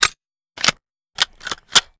minigun_reload_02.wav